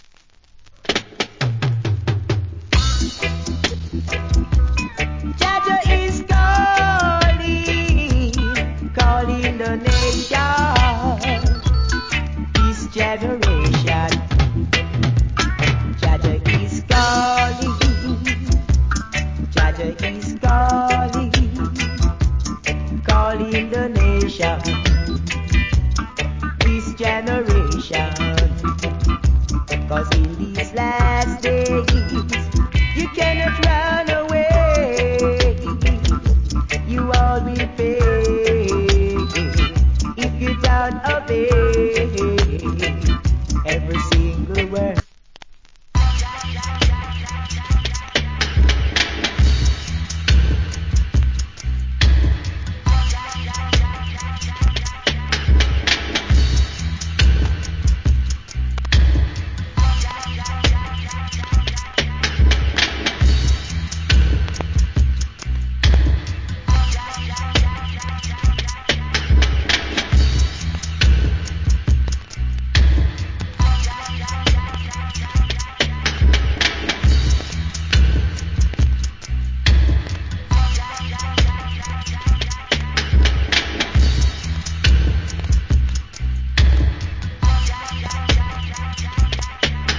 Early 80's. Killer Roots Rock Vocal.